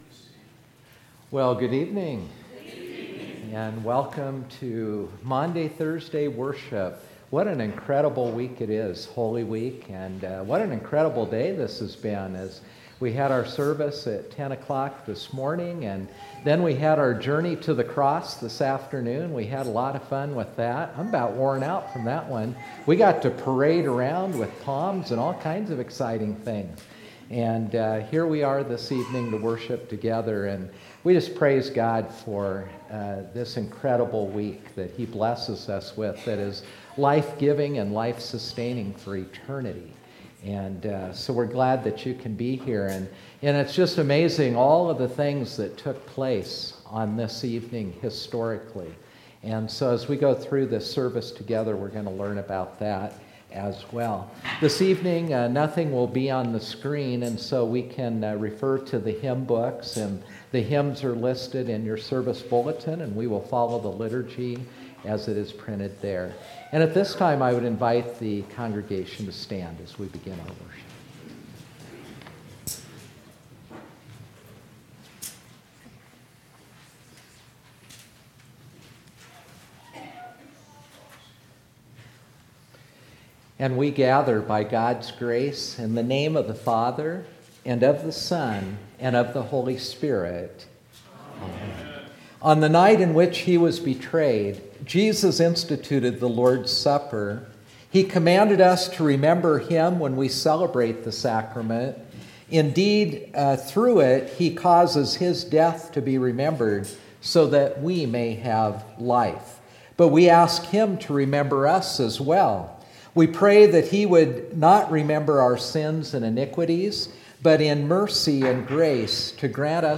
Maundy Thursday Worship